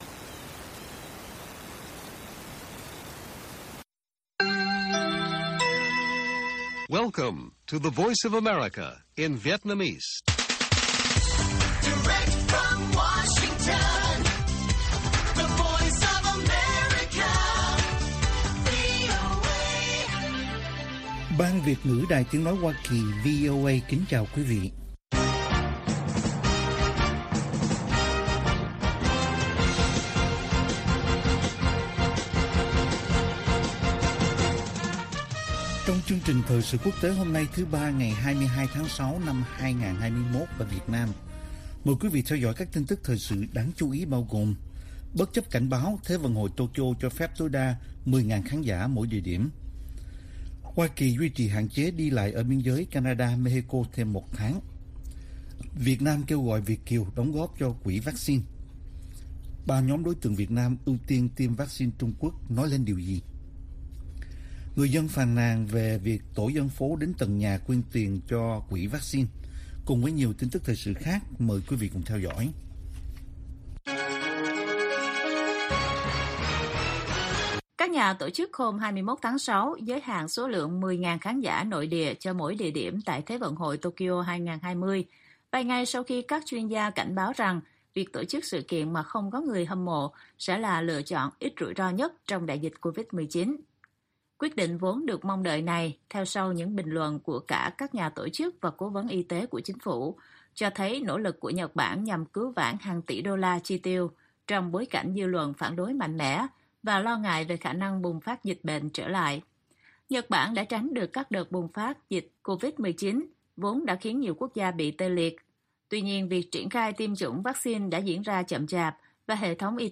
Bản tin VOA ngày 22/6/2021